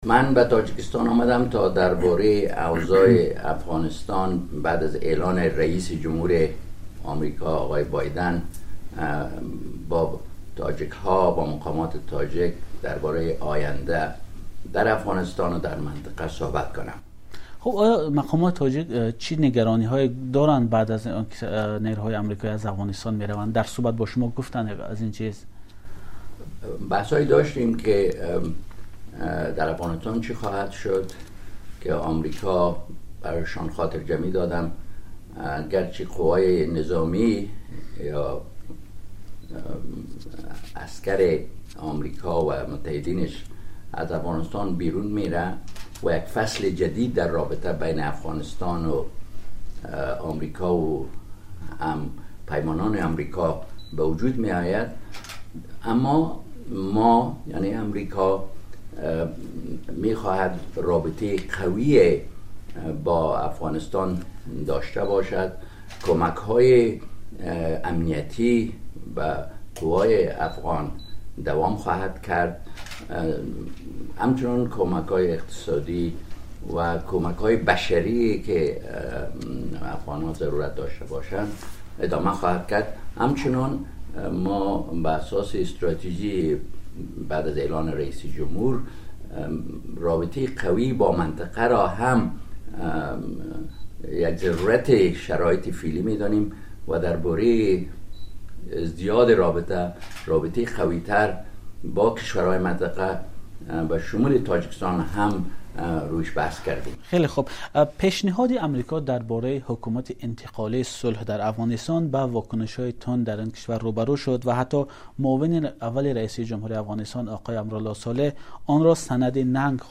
مصاحبه با خلیل‌زاد